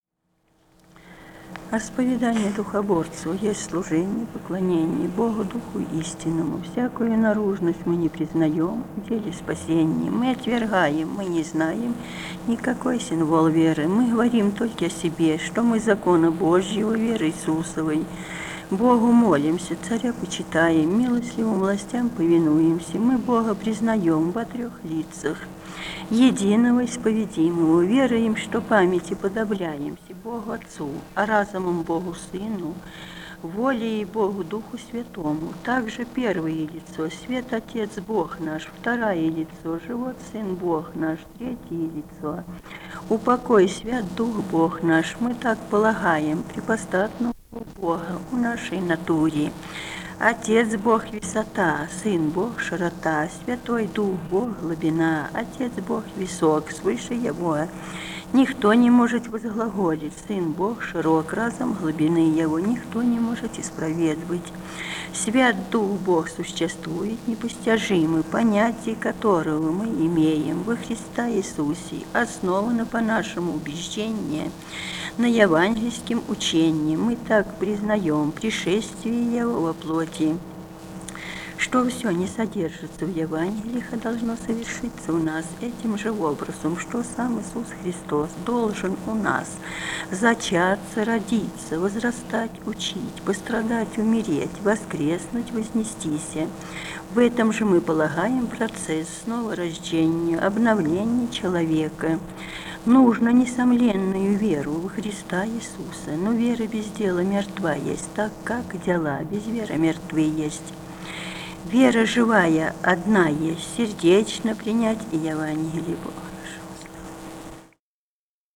Этномузыкологические исследования и полевые материалы
«Восповедание духоборцев» (текст из «Животной книги» духоборов). Пели: исполнители не указаны. Грузия, с. Гореловка, Ниноцминдский муниципалитет, 1971 г. И1310-24